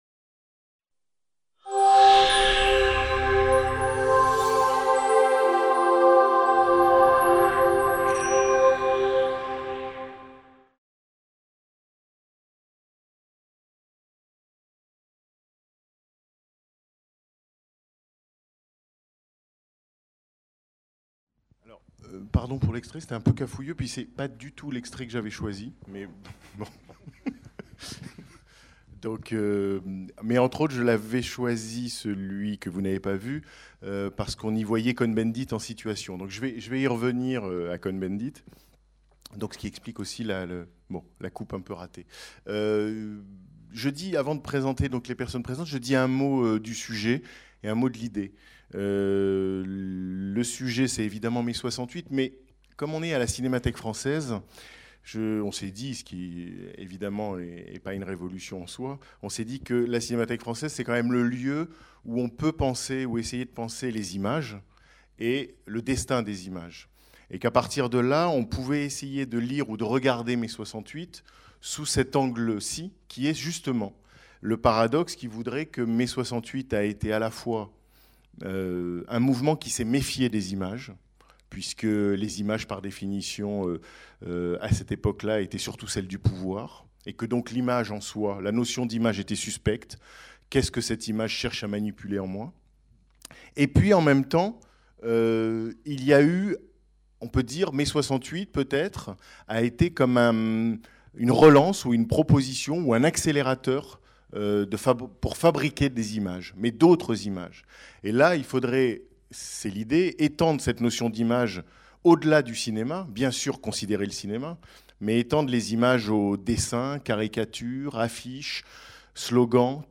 Mai 68 : images, écrits. Table ronde | Canal U